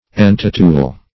Entitule \En*tit"ule\